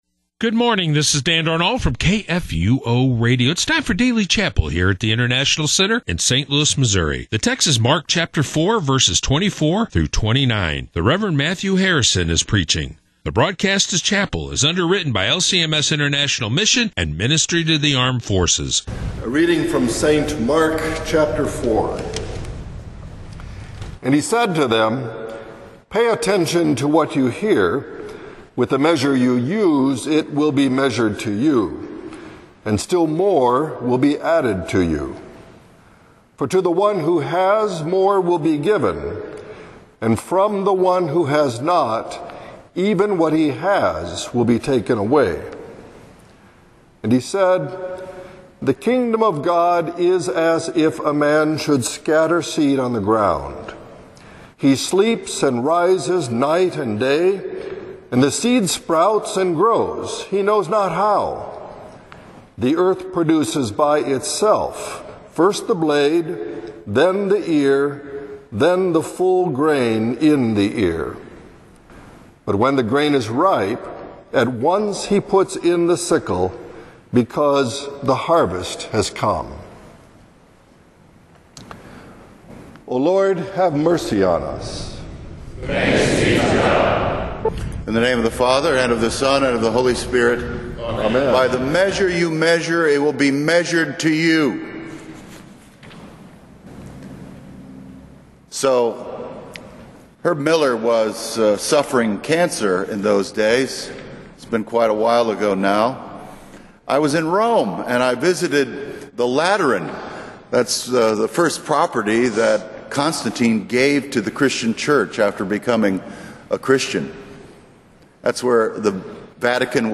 Rev. Matthew Harrison gives today’s sermon based on Mark 4:24-29.